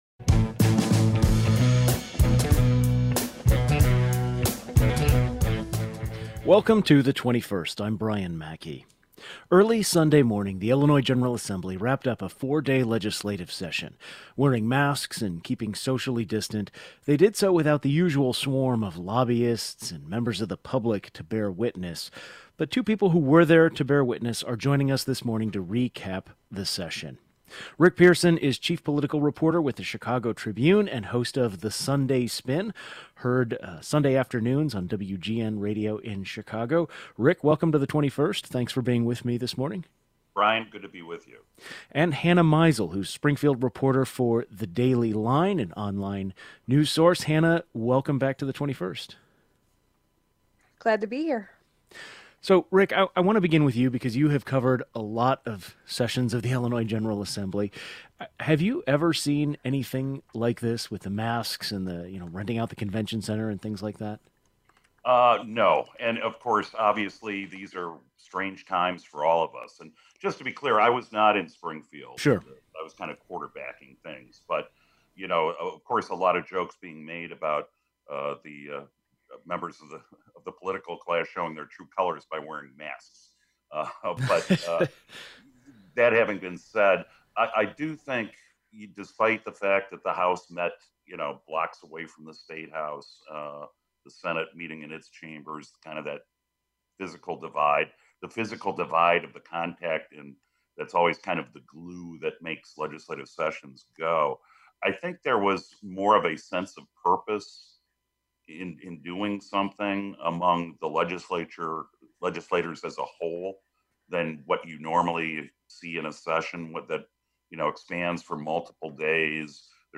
But Statehouse reporters were keeping tabs — mostly from a distance — and two joined us to recap the session.